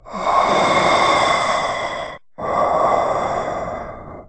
QuotKingKRoolBreathing.oga.mp3